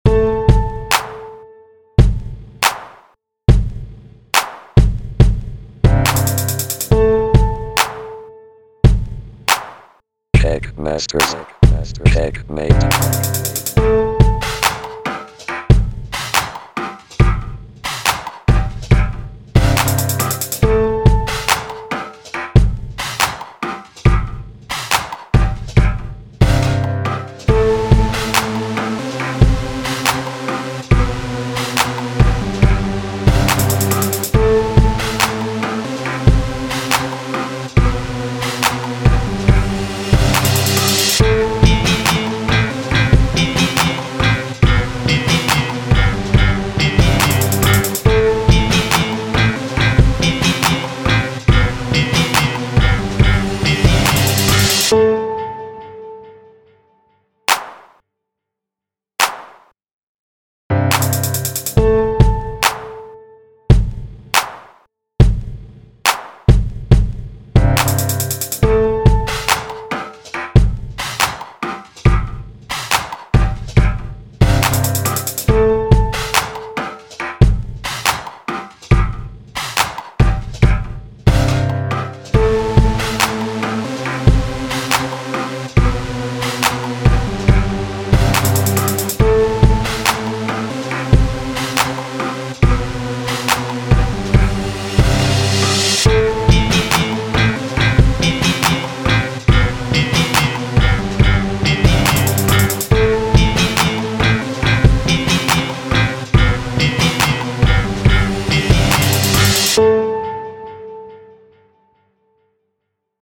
힙합 드럼를 이루는 기초 : Kick - Snare - HiHat
힙합 멜로디를 이루는 기초 : Base - Pad - Lead
"랩하기 좋은" 미니멀한 비트를 몇개 올려보려고 합니다.